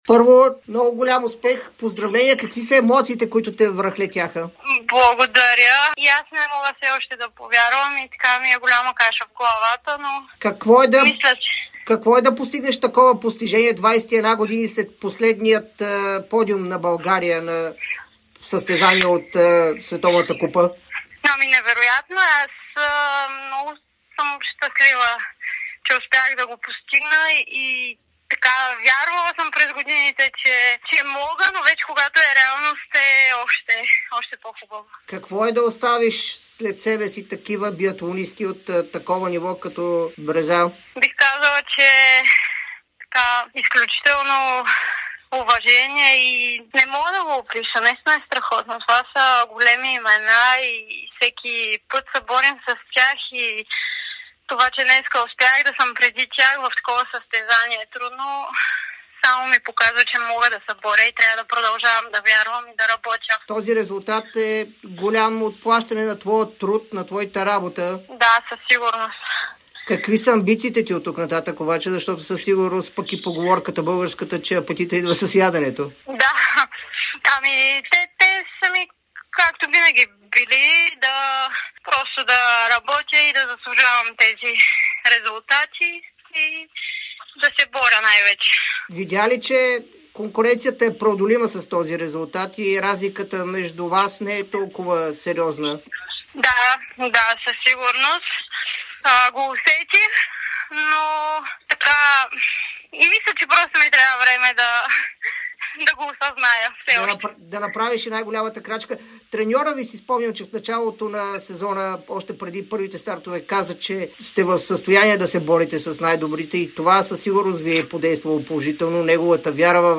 Водещата българска биатлонистка Милена Тодорова даде ексклузивно интервю пред Дарик радио и dsport броени минути, след като завърши на трето място в спринта от Световната купа по биатлон в германския зимен център Оберхоф.